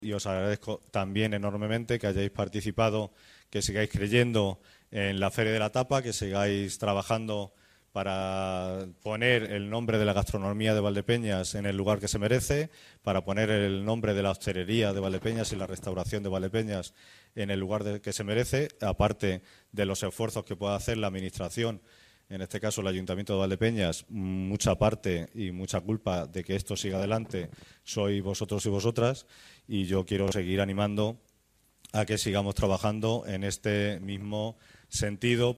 En la entrega de premios, que ha tenido lugar en el salón de plenos de la Casa Consistorial, el Teniente de alcalde de Cultura, Turismo, Educación y Festejos, Manuel López Rodríguez, ha agradecido a los participantes que “sigáis creyendo en la Feria de la Tapa y que sigáis trabajando para poner el nombre de la gastronomía y hostelería de Valdepeñas en el lugar que se merece, por lo que os animo a seguir trabajando en este sentido junto a la administración”.